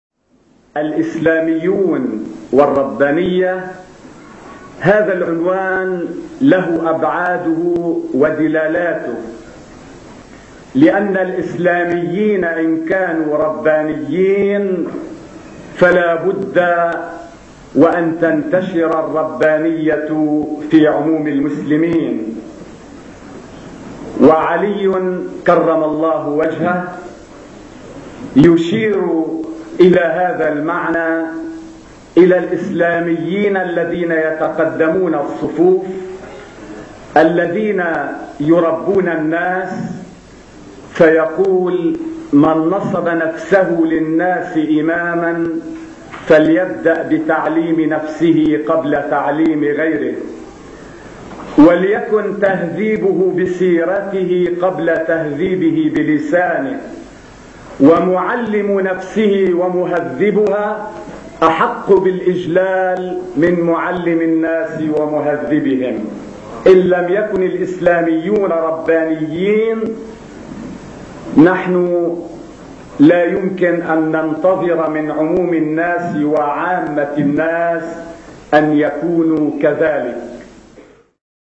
خطب ومحاضرات الداعية الشيخ فتحي يكن